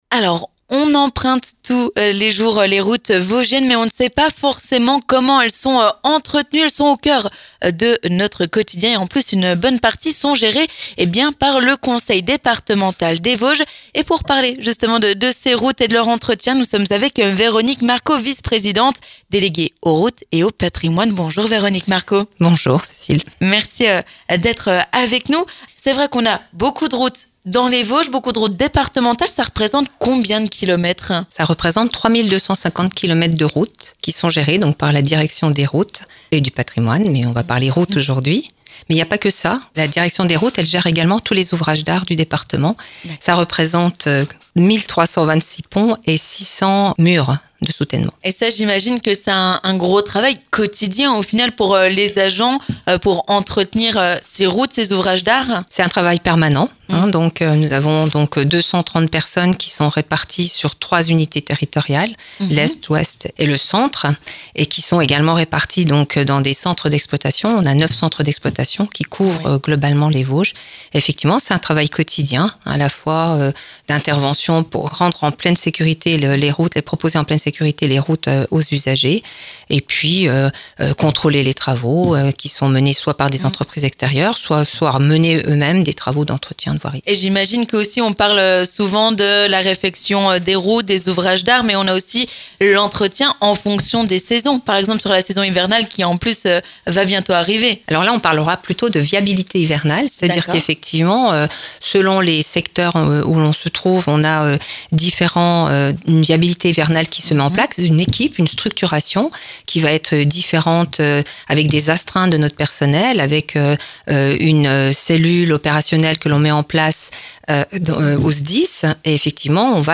Entretien des routes : l'élue Véronique Marcot revient sur la fermeture de la Route des Crêtes
Avec notamment la fermeture annuelle de la route des Crêtes, qui ne sera plus accessible dès ce lundi 17 novembre. On en parle avec Véronique Marcot, vice-présidente au Conseil départemental des Vosges déléguée aux routes et au patrimoine.